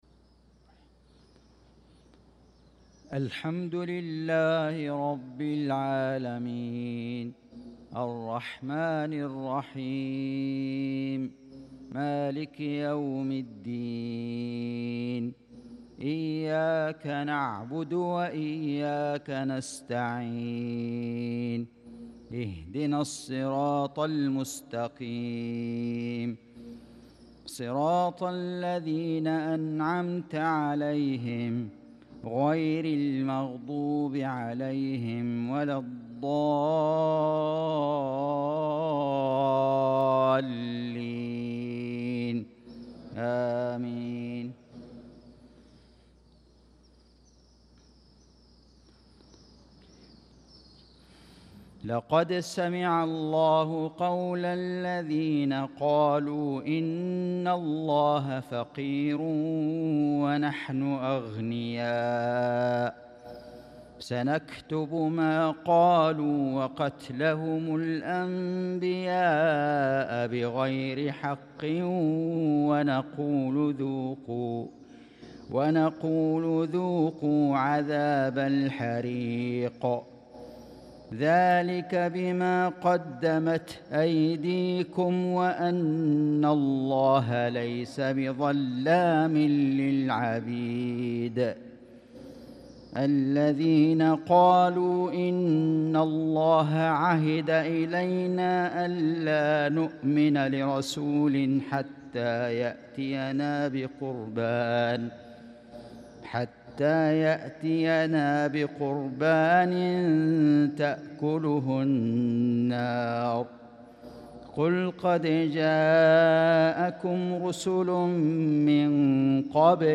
صلاة المغرب للقارئ فيصل غزاوي 4 ذو القعدة 1445 هـ
تِلَاوَات الْحَرَمَيْن .